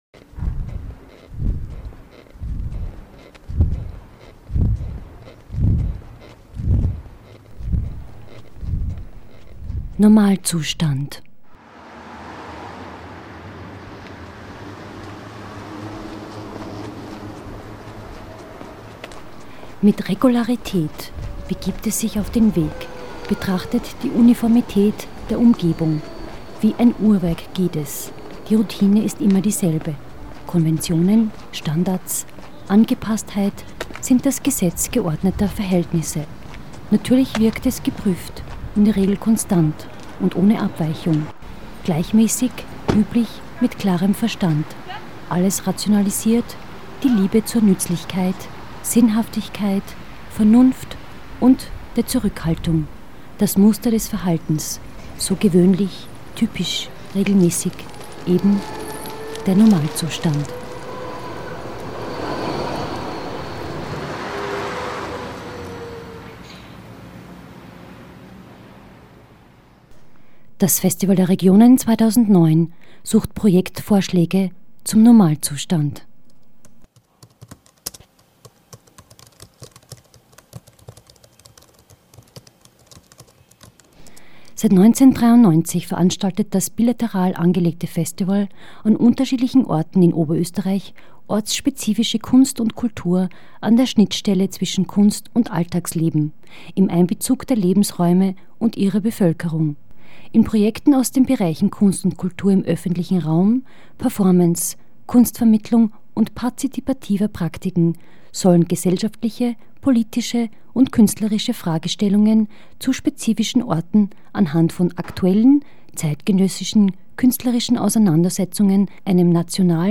Format: Stereo 44kHz